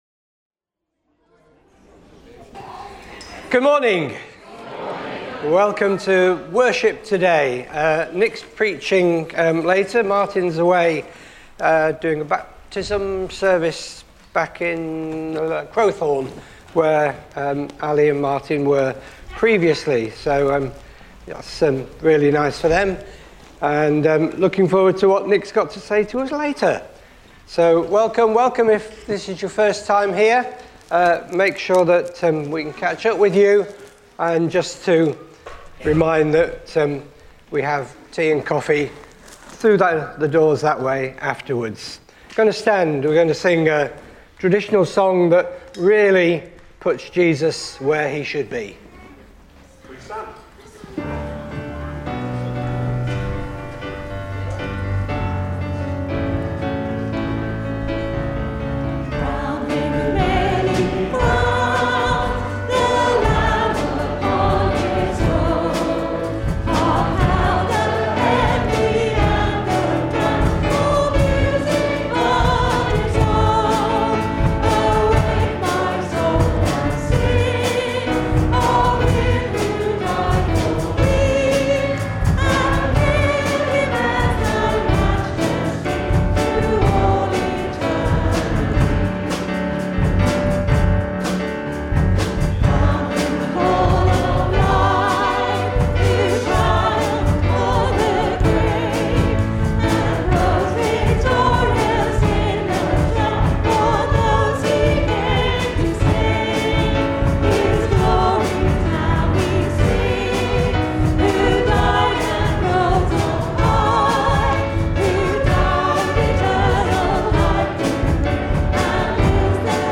1 February 2026 – Morning Service
Service Type: Morning Service